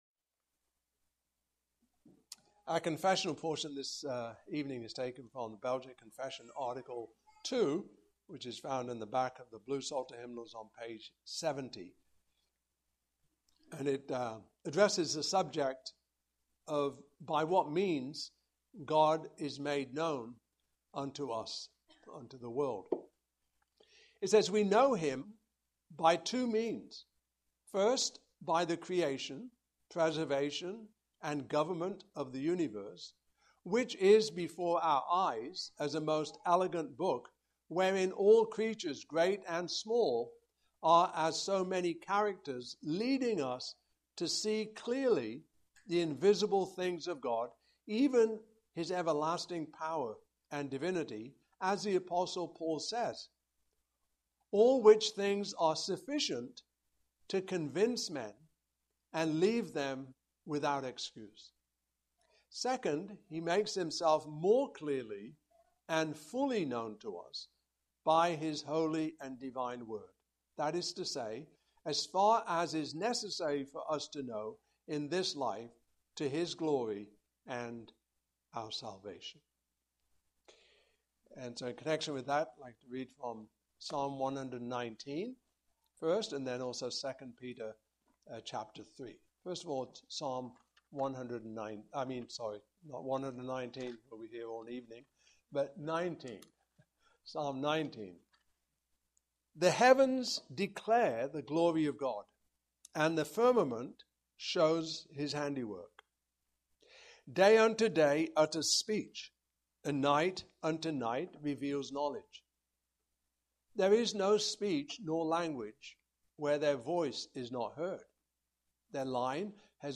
Passage: Psalm 19:1-16, 2 Peter 3:1-18 Service Type: Evening Service